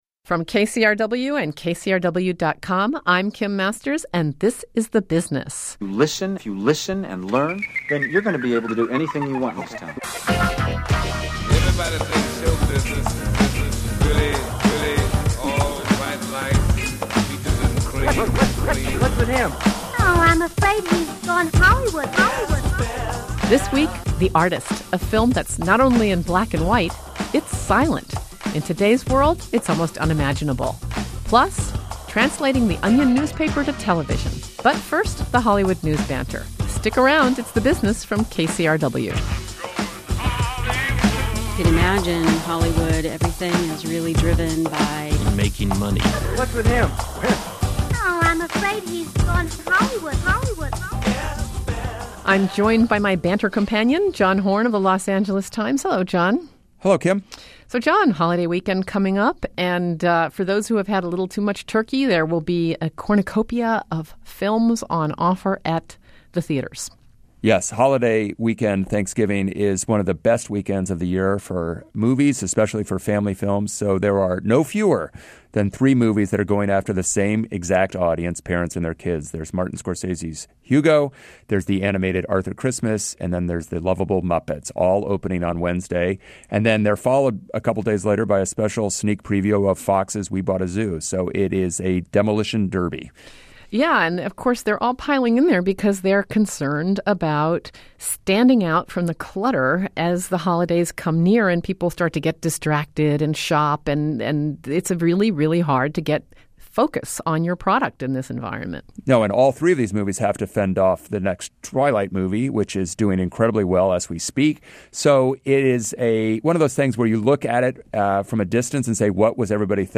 Writer/director Michel Hazanavicius and producer Thomas Langmann talk about how and why they made The Artist.
Plus, we talk with two of the comedy minds behind the Onion News Network.